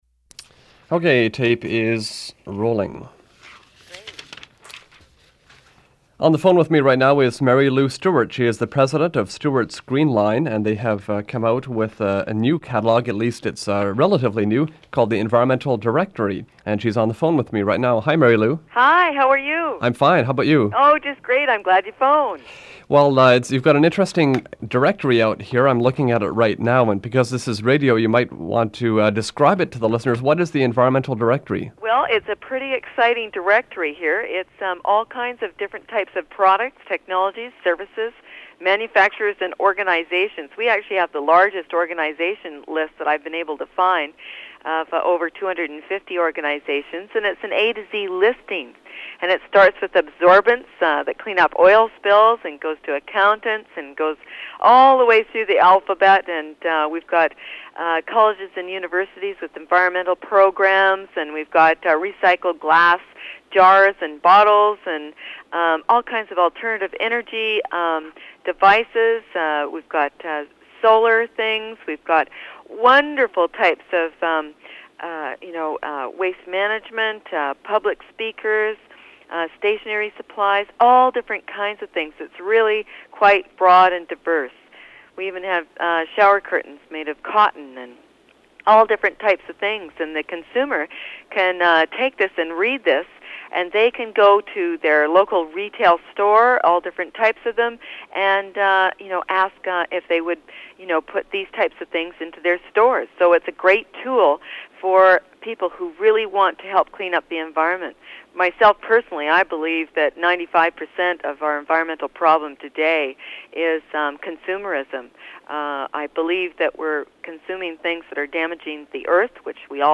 Recording of an interview